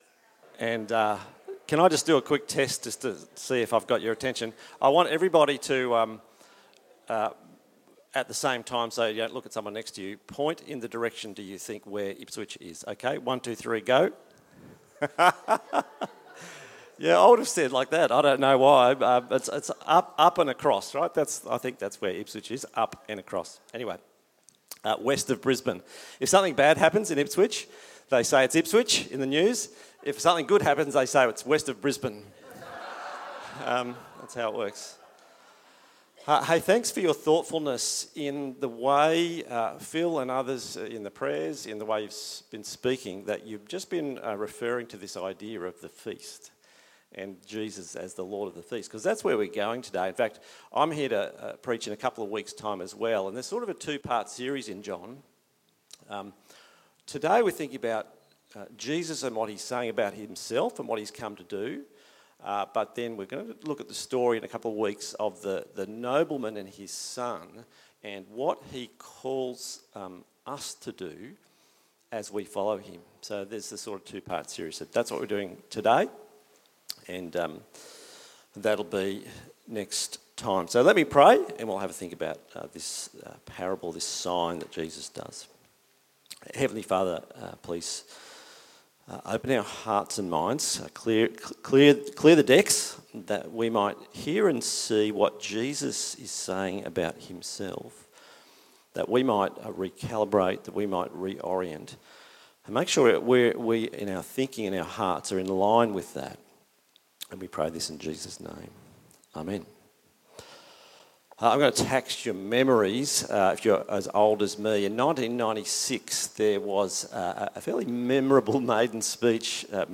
Sermons
Listen to our sermons from Sunday here